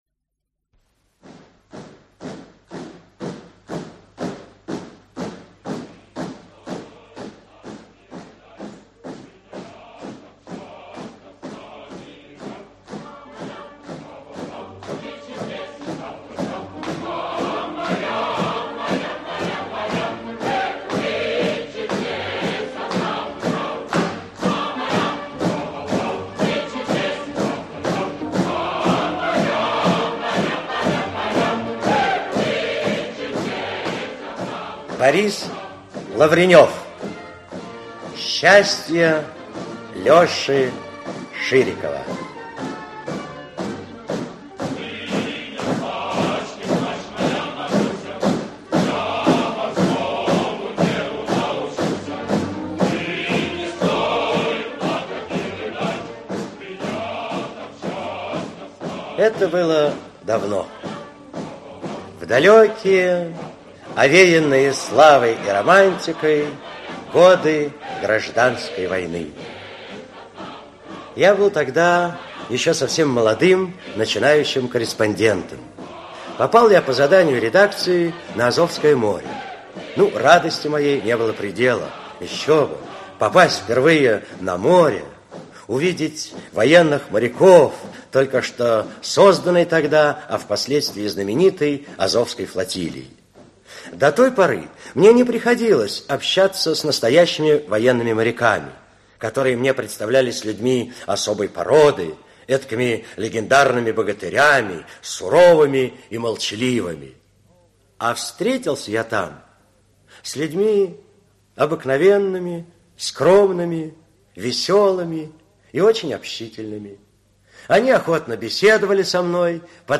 Аудиокнига Счастье Леши Ширикова | Библиотека аудиокниг
Aудиокнига Счастье Леши Ширикова Автор Борис Лавренев Читает аудиокнигу Актерский коллектив.